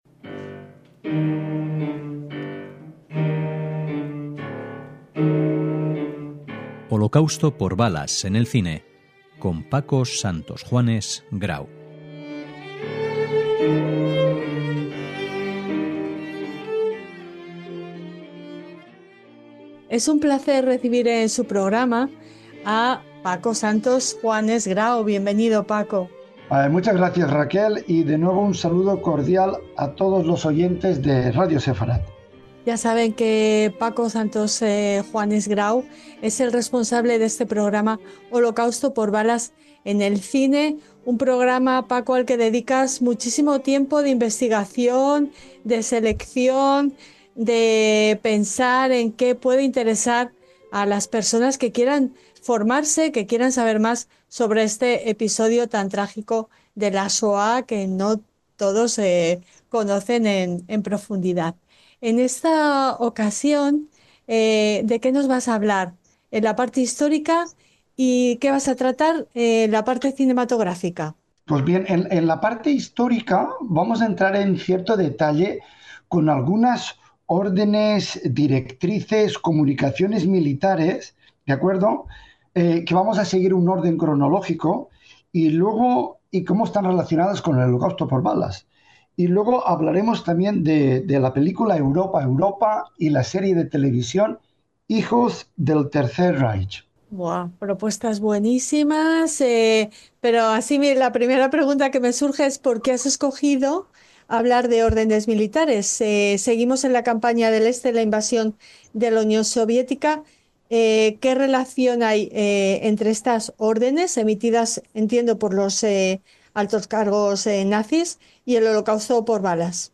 Discurso de A. Hitler, fragmento del documental El ascenso de los nazis.